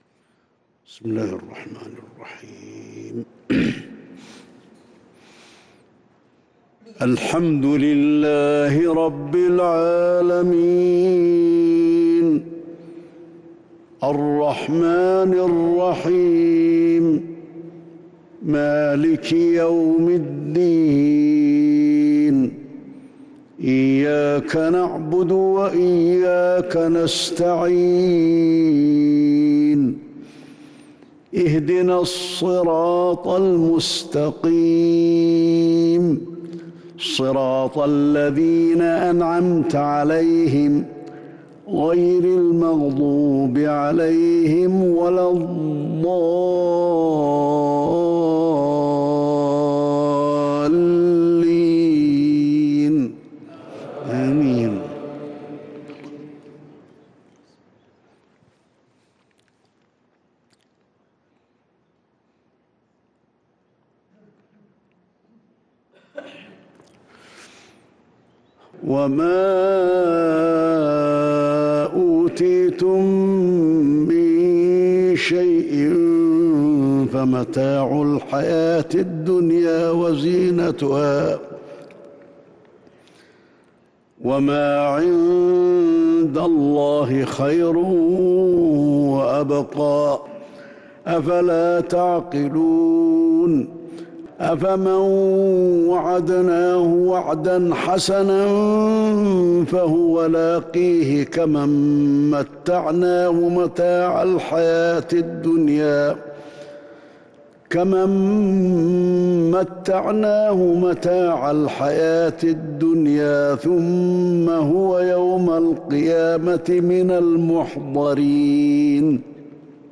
عشاء الثلاثاء 7-7-1443هـ من سورة القصص | lsha prayer fromSurah Al-Qasas 8-2-2022 > 1443 🕌 > الفروض - تلاوات الحرمين